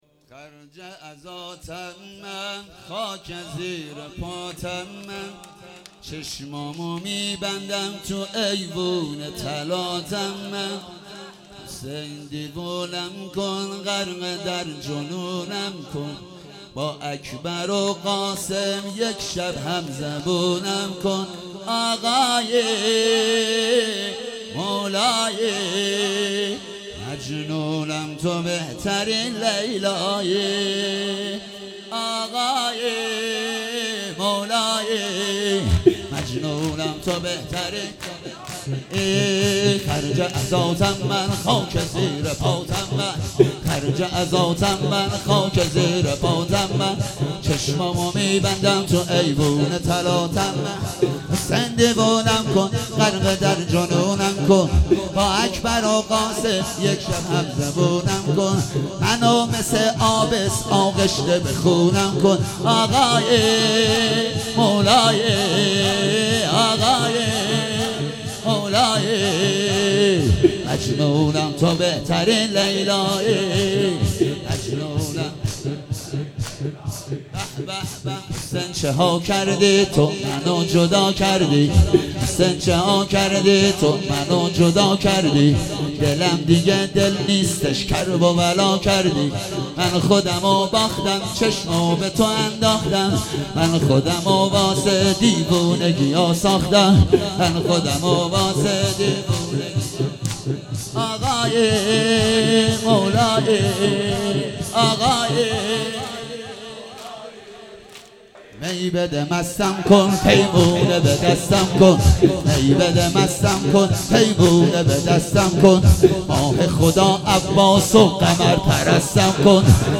فاطمیه 96 - شب چهارم - شور - خرج عزاتم من